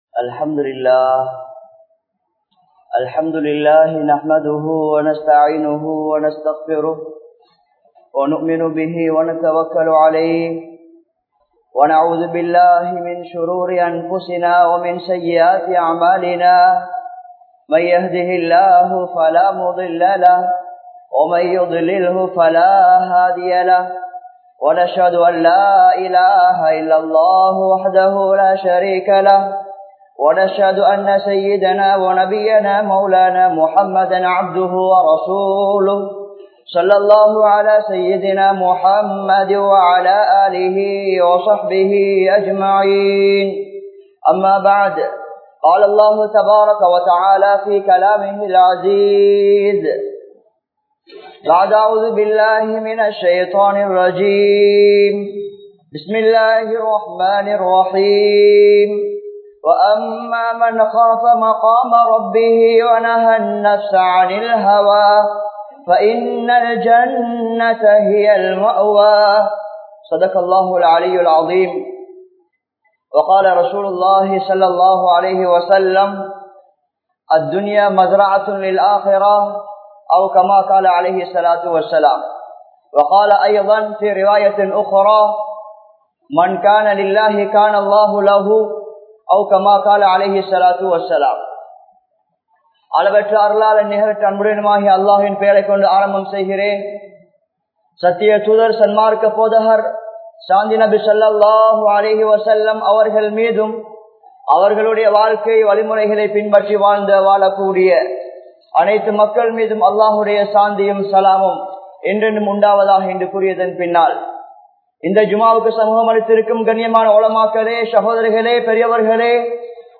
Kattankudy, Mohideen Grand Jumua Masjith